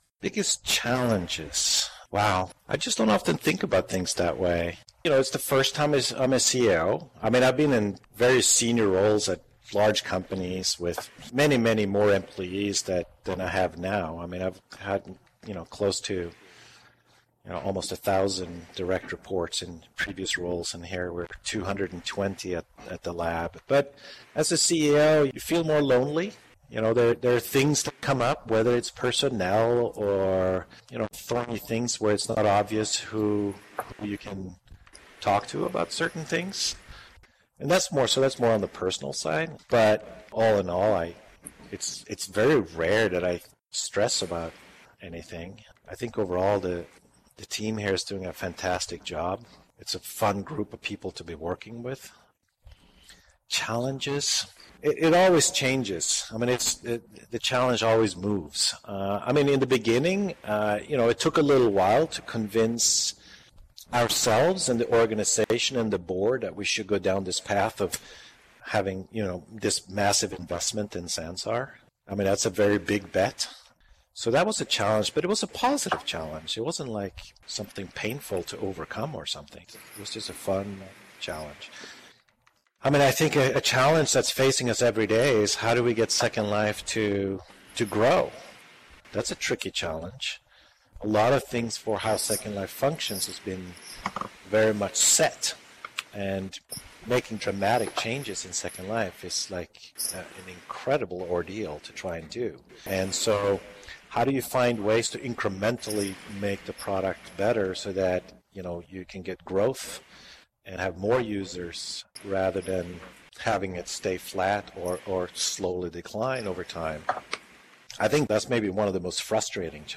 answering questions from hosts